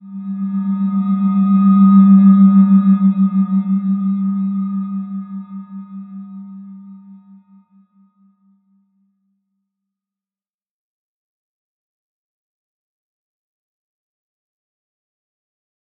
Slow-Distant-Chime-G3-f.wav